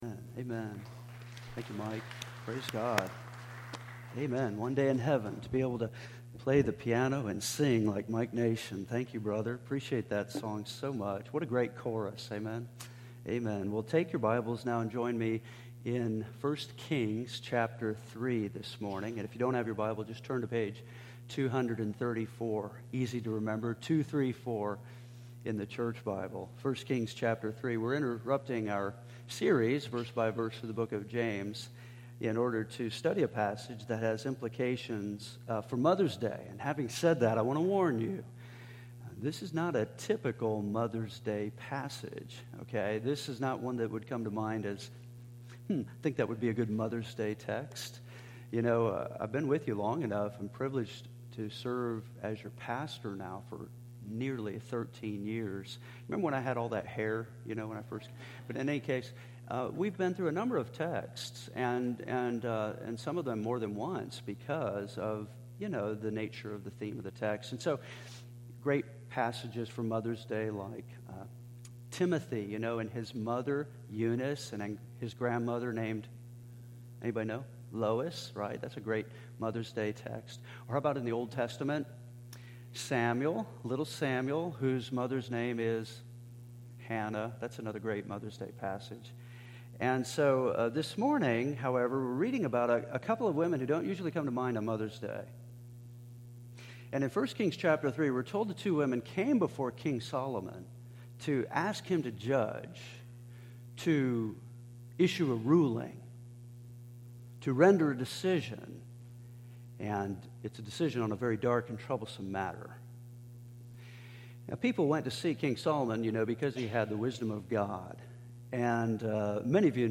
A Real Mom-A Mother’s Day Message